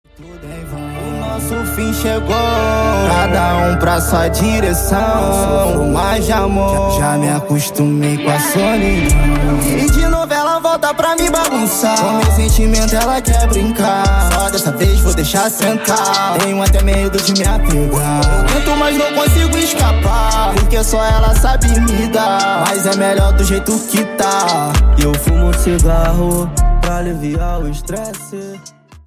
HipHop / Rock